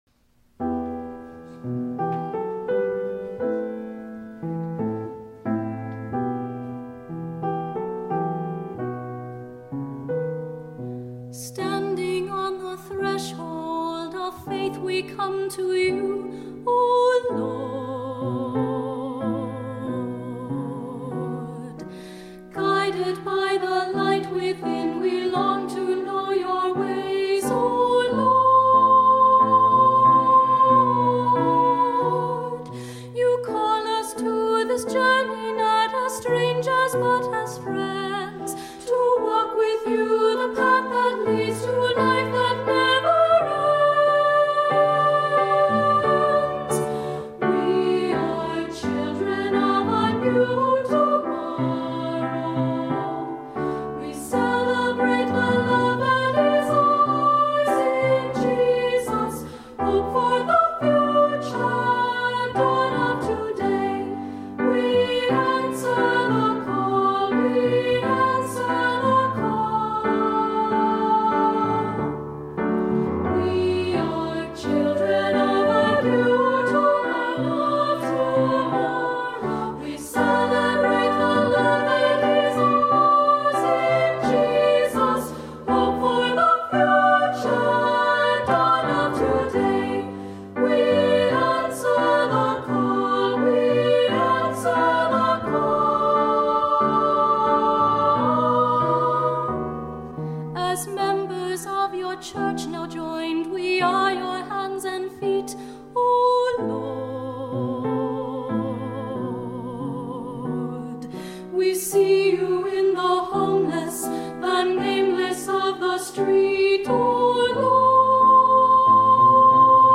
Voicing: Unison Choir, Soloist, Descant